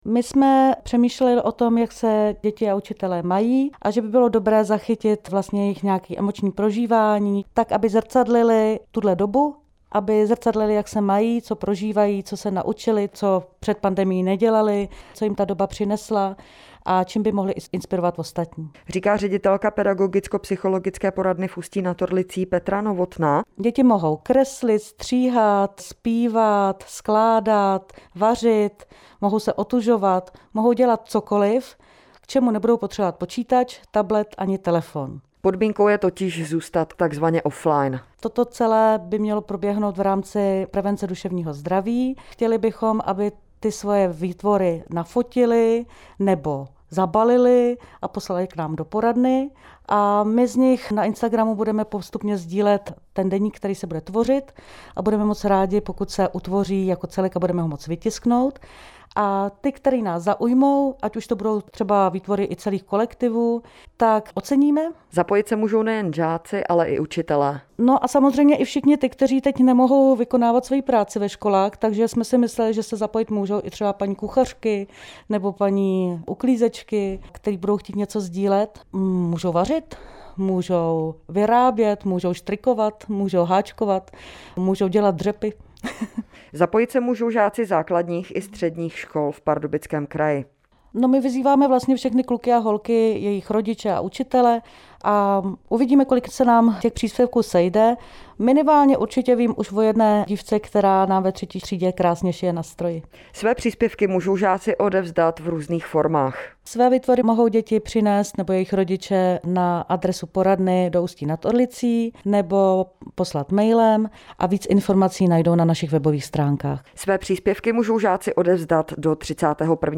O Deníku týhle doby jste mohli slyšet i v rádiu na stanicích Českého rozhlasu - Radiožurnál, Rádio Junior a Český rozhlas Pardubice.